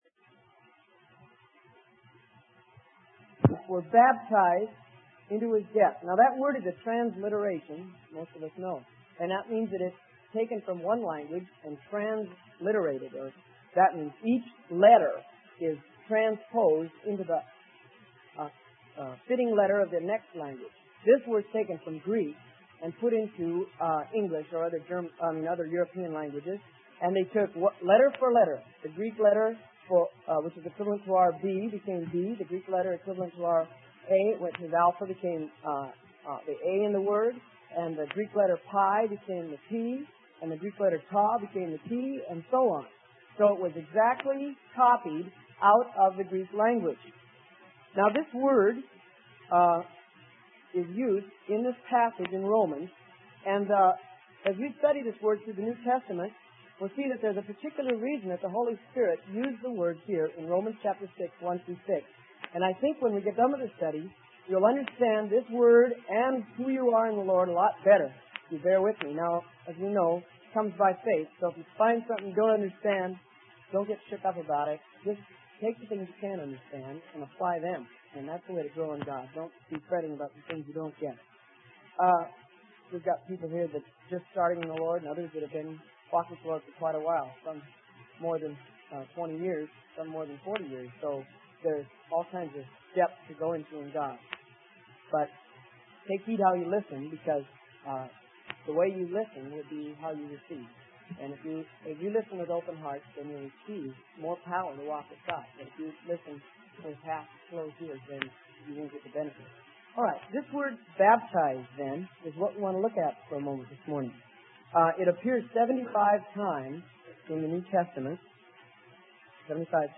Sermon: Terminology Series - Part 6 - Freely Given Online Library